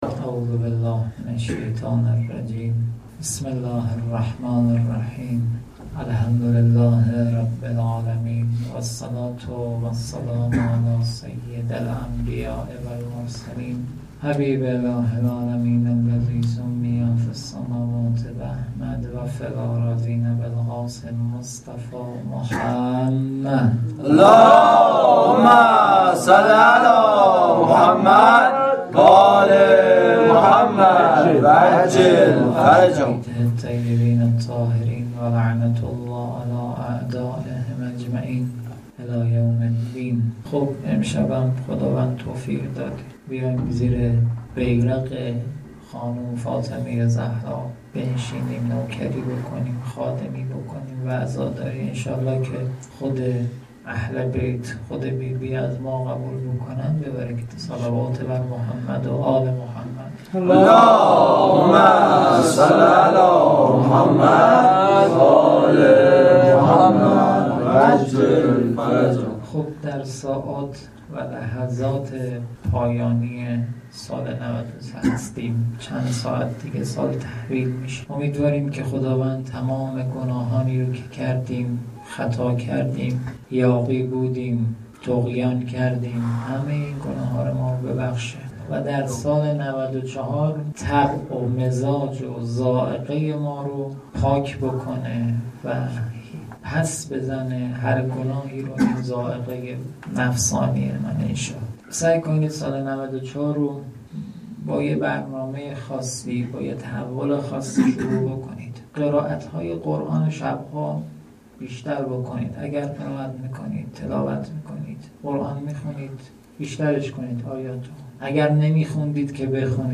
منبر شب دوم فاطمیه 1393